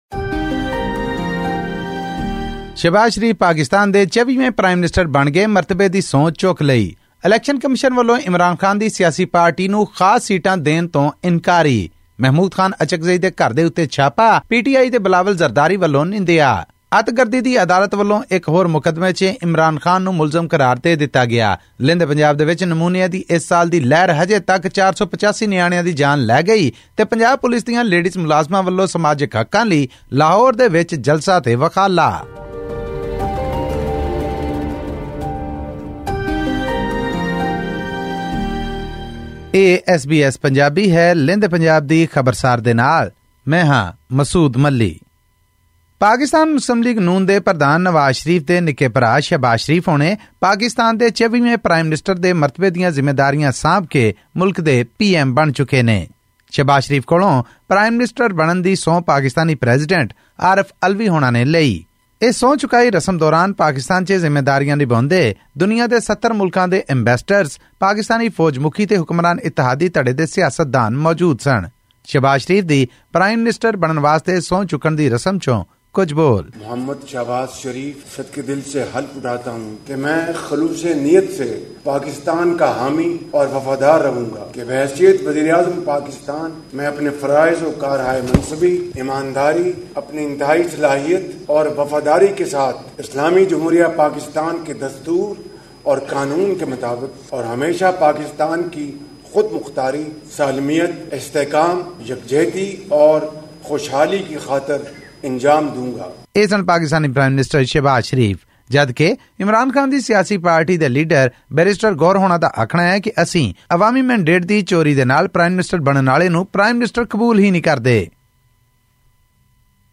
ਇਹ ਅਤੇ ਪਾਕਿਸਤਾਨ ਦੀਆਂ ਹੋਰ ਖਬਰਾਂ ਲਈ ਸੁਣੋ ਆਡੀਓ ਰਿਪੋਰਟ....